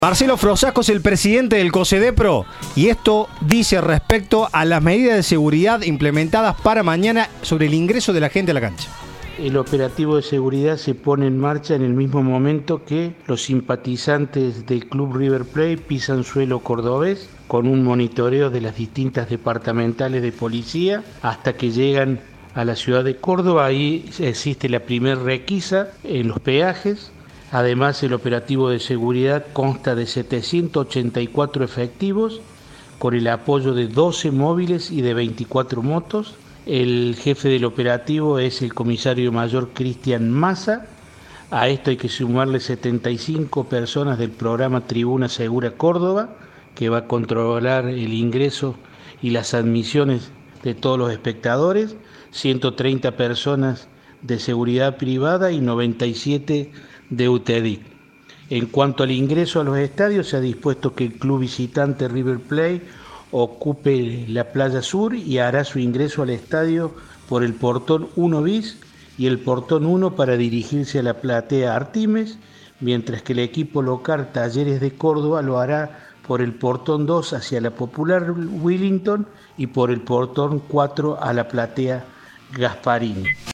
Marcelo Frossasco, titular del Consejo de Seguridad Deportiva de la provincia de Córdoba (Cosedepro), detalló en diálogo con Cadena 3 el operativo de seguridad policial planificado para el encuentro de la Copa de la Liga Profesional de este miércoles, en el estadio Mario Alberto Kempes, entre Talleres y River.